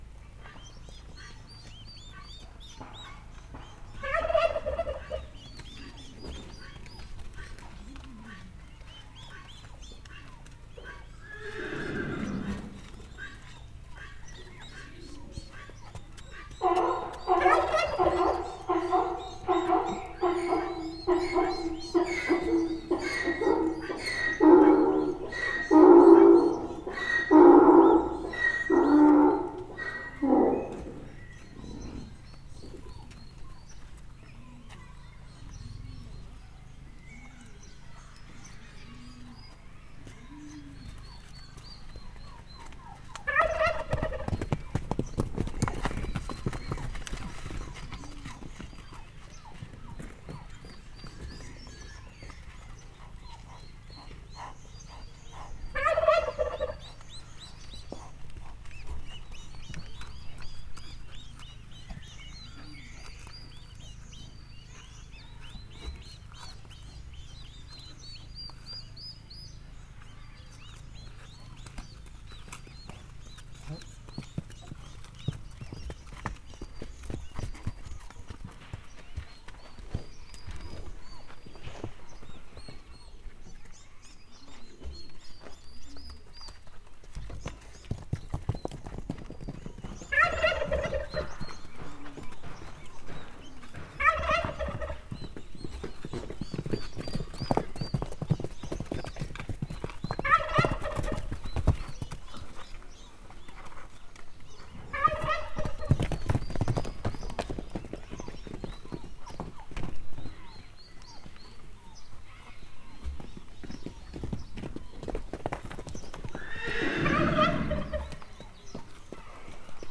poniutanepekedik_bognyeritszalad_gyongytyukszel03.39.wav